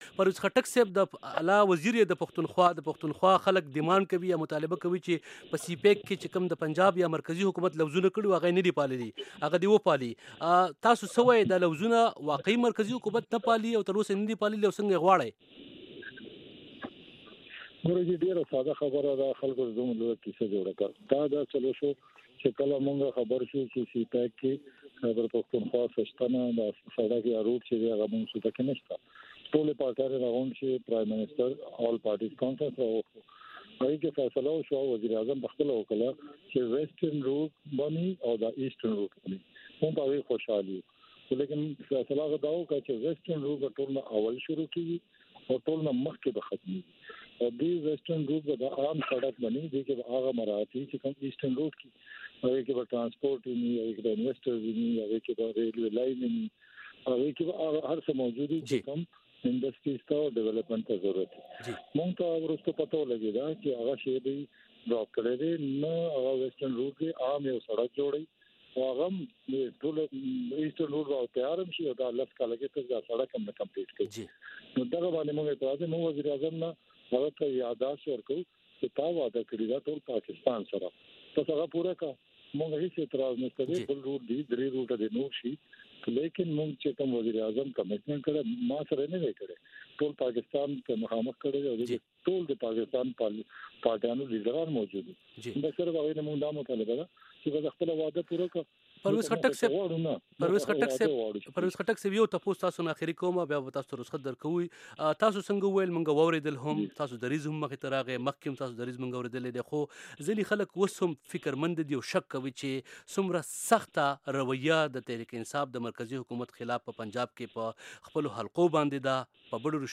ځانگړې مرکه